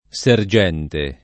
sergente [ S er J$ nte ]